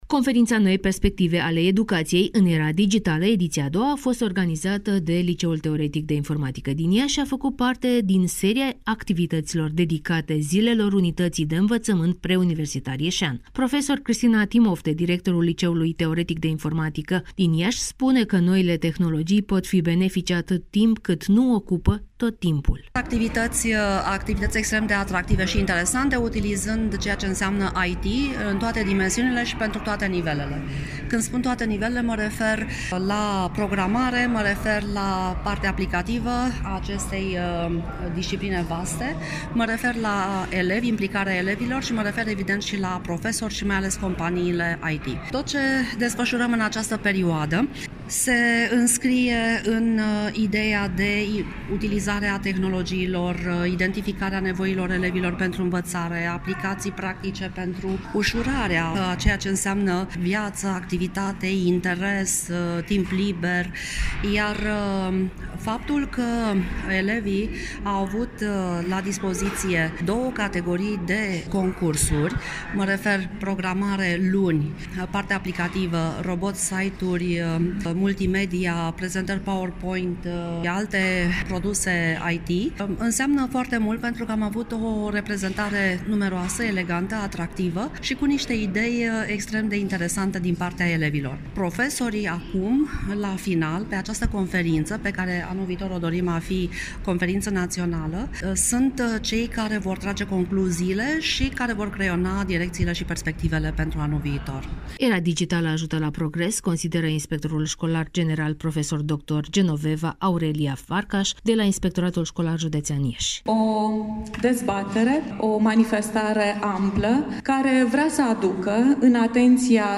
(REPORTAJ) Conferința ” Noi perspective ale educației în era digitală”, ediția a II a
Astăzi de la ora 10.00 la Aula Magna Universității Tehnice din Iași a avut loc conferința ” Noi perspective ale educației în era digitală”, ediția a II a. Evenimentul a fost organizat de Liceul Teoretic de Informatică din Iași.